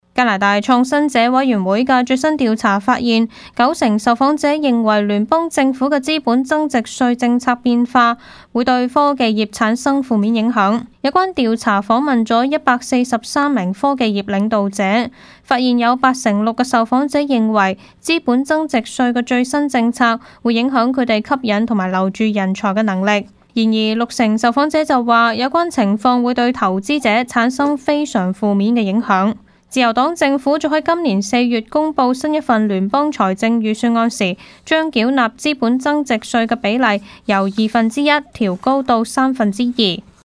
news_clip_19770.mp3